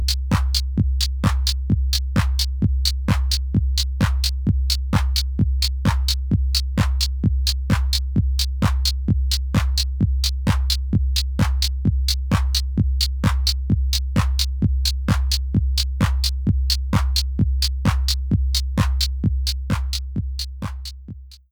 20ms:
You’ll notice that with claps, it’s relatively unimportant. With two kicks on top of each other, it becomes problematic.